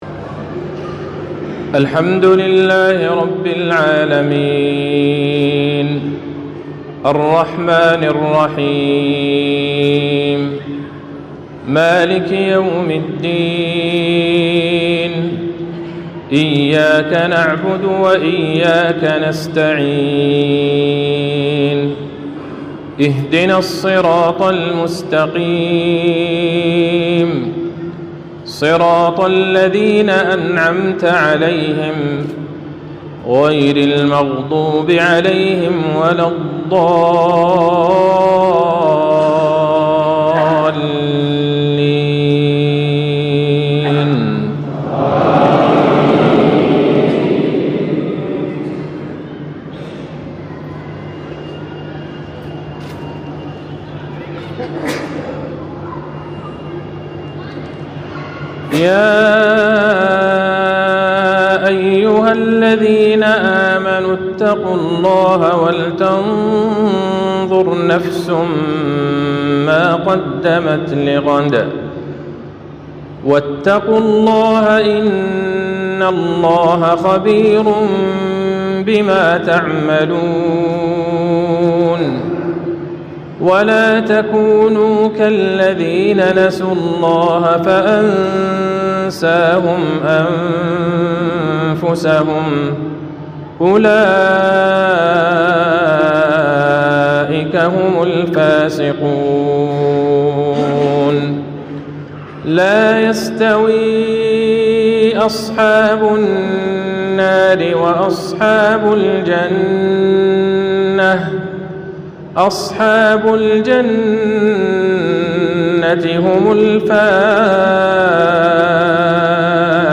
تلاوات الحرمين